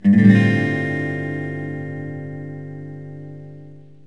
G7sus4.mp3